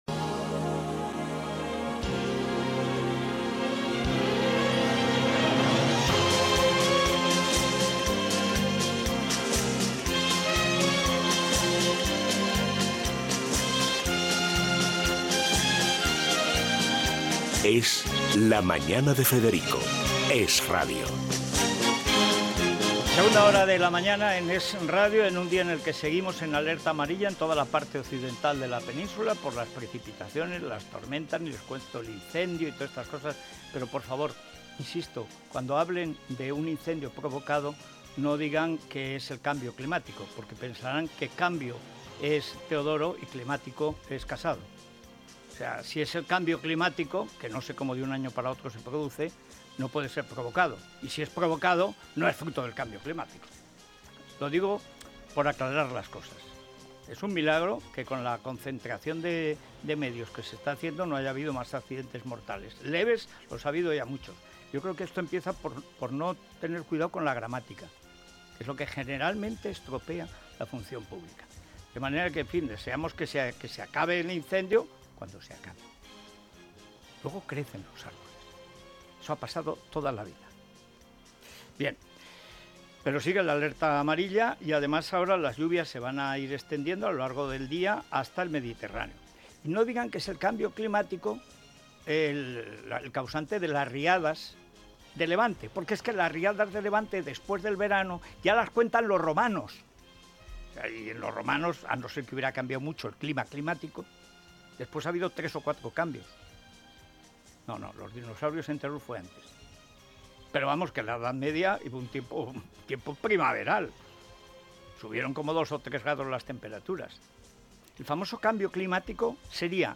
bdb8b63c36e454ccd9911b7f7a302aeb1e293ccb.mp3 Títol esRadio Emissora esRadio Cadena esRadio Titularitat Privada estatal Nom programa Es la mañana de Federico Descripció Indicatiu del programa, opinió sobre els incendis forestals, les riuades i el canvi climàtic.
Declaracions de Pedro Sánchez i Pablo Casado (Partido Popular). Informació sobre els impostos de l'electricitat.
Gènere radiofònic Info-entreteniment